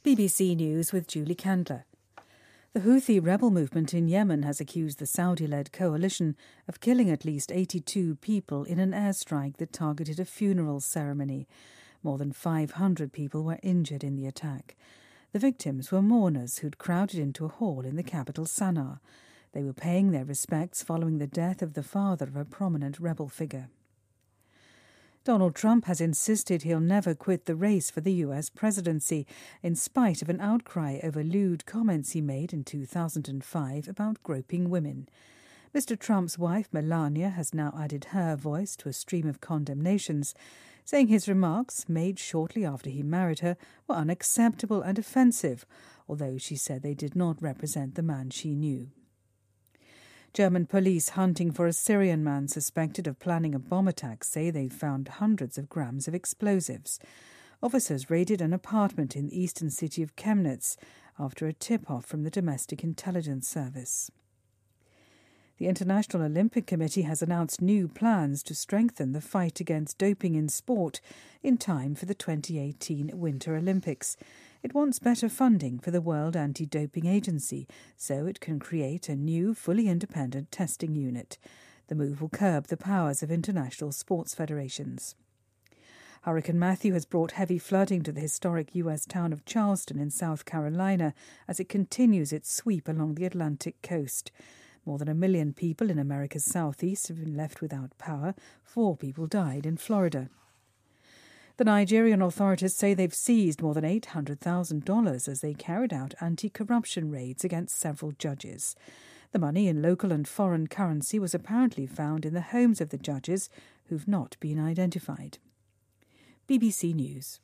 BBC news,特朗普:不会因猥亵女性录音曝光退选
日期:2016-10-10来源:BBC新闻听力 编辑:给力英语BBC频道